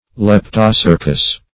Search Result for " leptocercous" : The Collaborative International Dictionary of English v.0.48: Leptocercal \Lep`to*cer"cal\, (l[e^]p`t[-o]*s[~e]r"kal), Leptocercous \Lep`to*cerc"ous\ (l[e^]p`t[-o]*s[~e]rk"[u^]s),a. (Zool.) Having a long slender tail.